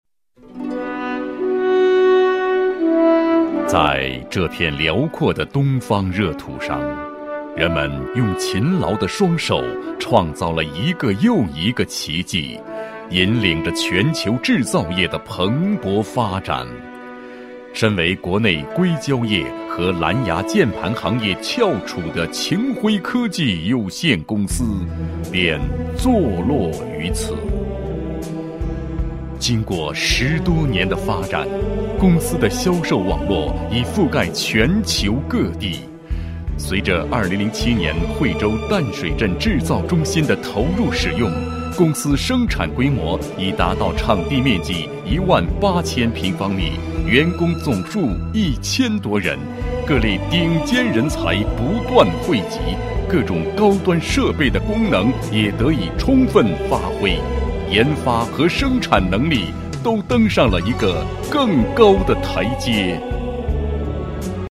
成熟稳重 企业专题
磁性沉稳男音，高性价比。企业专题解说、可以尝试不同题材配音。